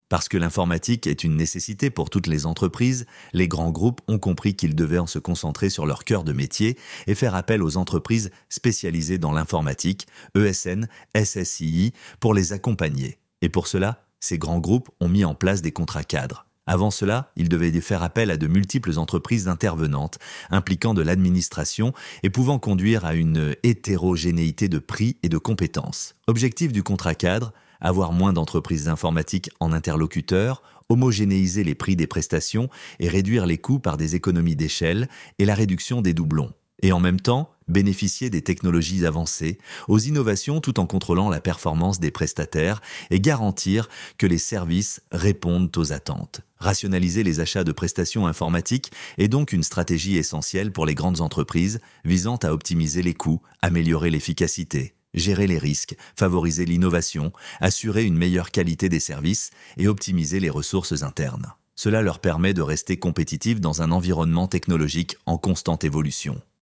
Pour une lecture automatique de l’article (9mn30), cliquez sur le bouton de démarrage :